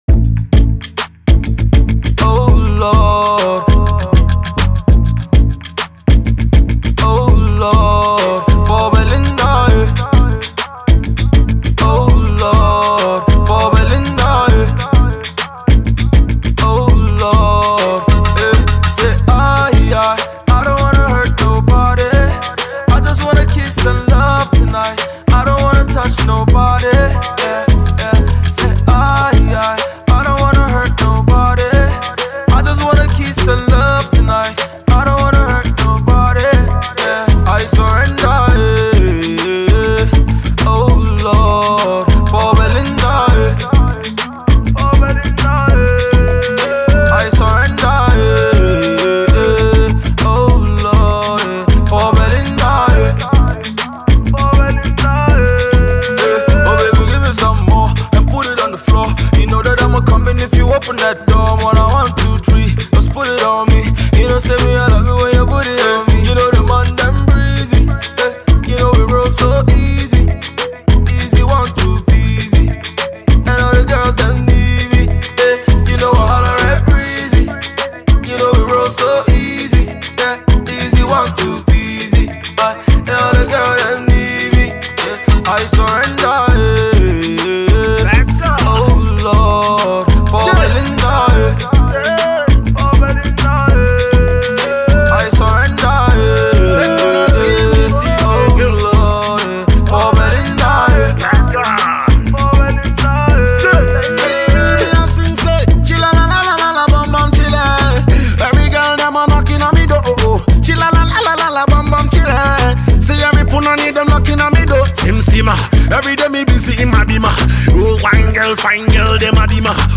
struck a perfect balance between singing and rapping